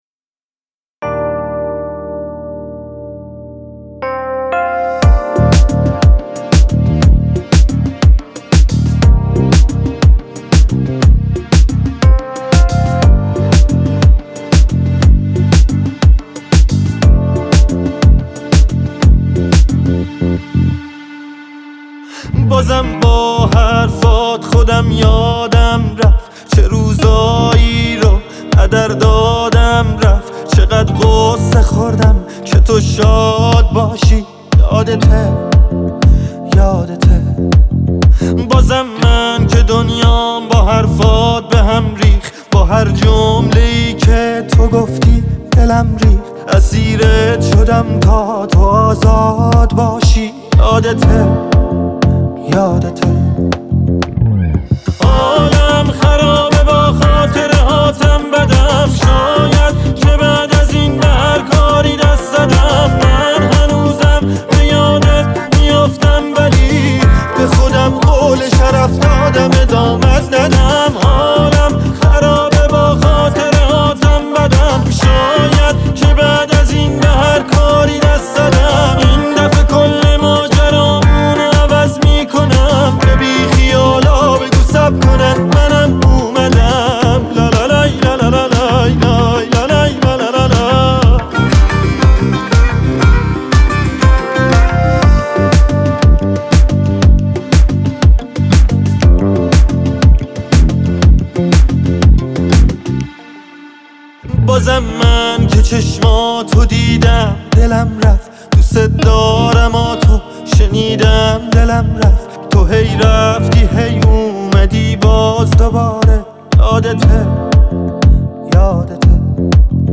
آهنگهای پاپ فارسی
ریمیکس